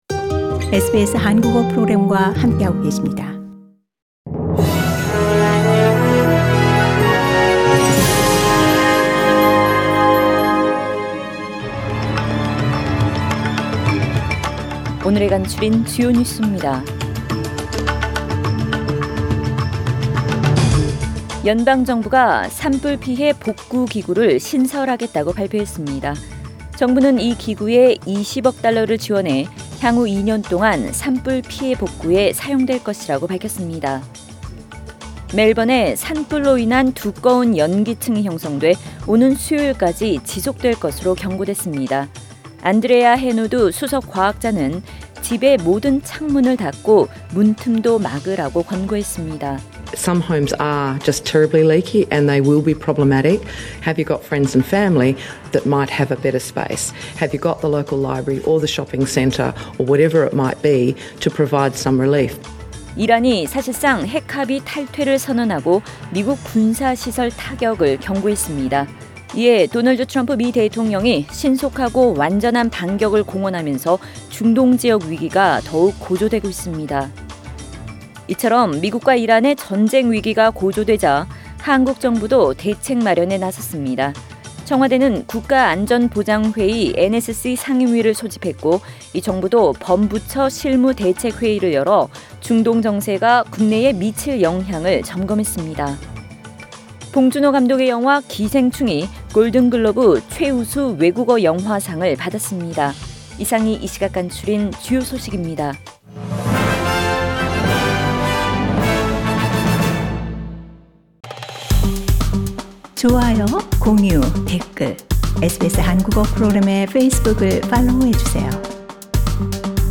SBS Korean News Source: SBS Korean